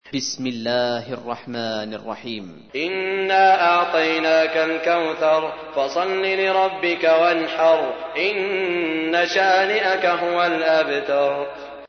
تحميل : 108. سورة الكوثر / القارئ سعود الشريم / القرآن الكريم / موقع يا حسين